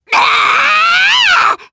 Viska-Vox_Attack4_jp.wav